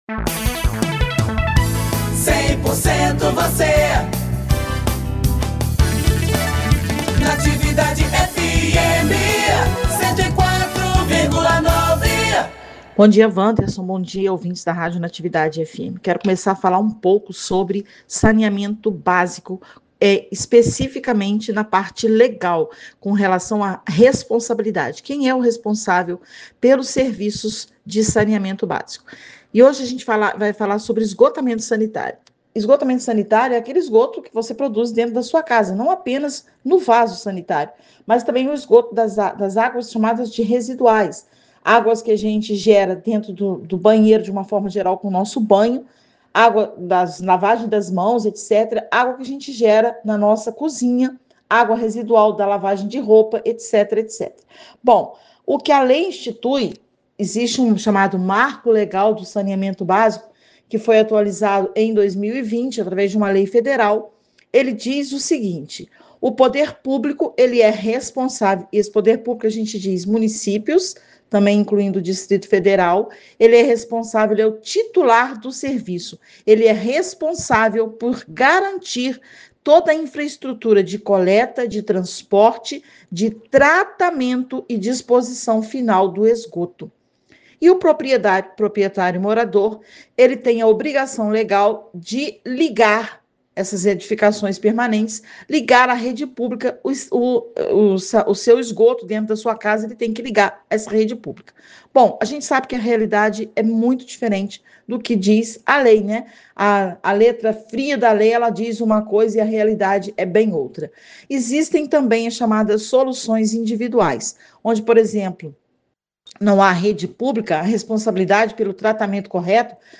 Podcast: De quem é a responsabilidade pelas ações de saneamento básico? Bióloga esclarece – OUÇA